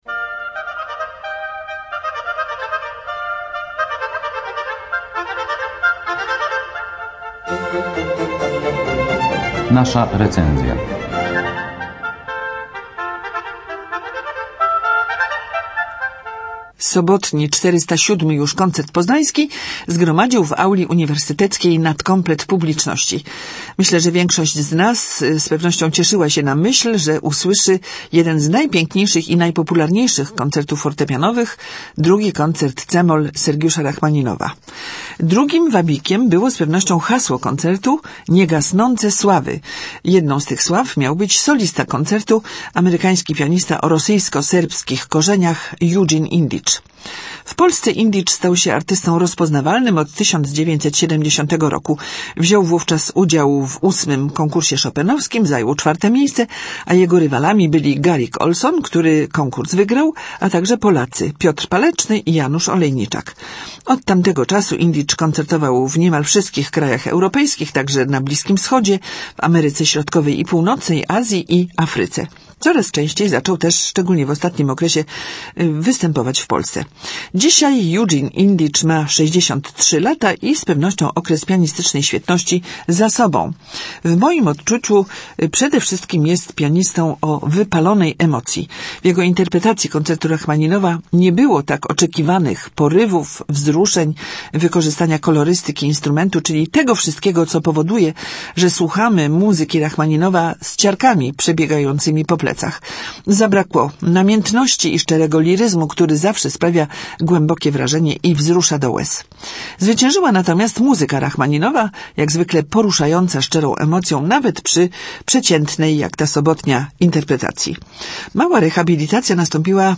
w Auli Uniwersyteckiej
pianisty